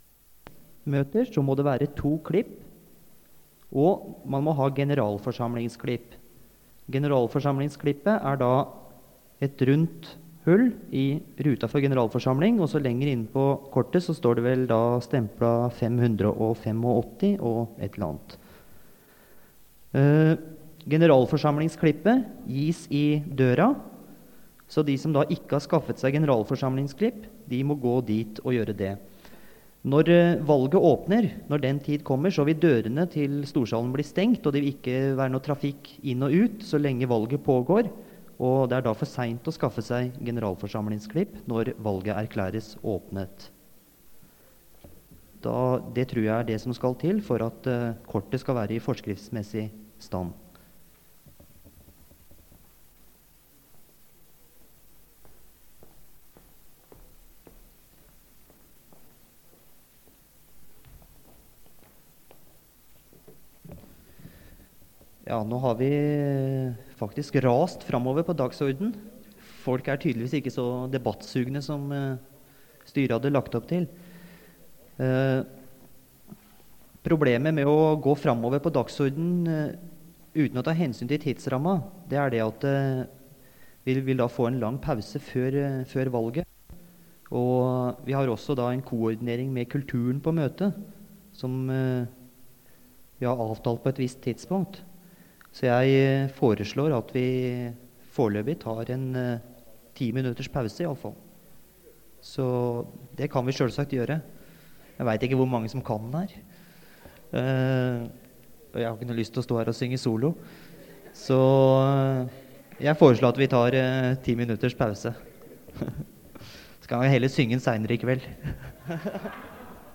Det Norske Studentersamfund, Generalforsamling, 05.12.1980 (fil 5-8:8)